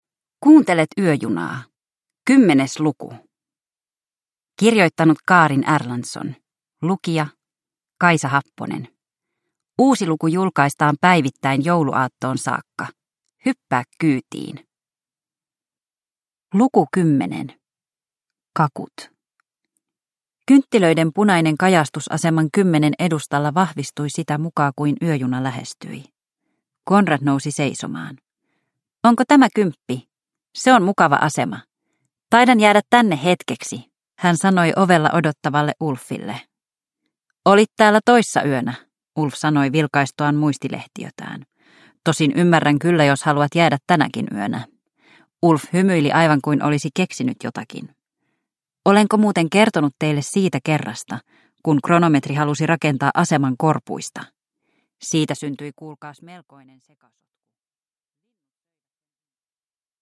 Yöjuna luku 10 – Ljudbok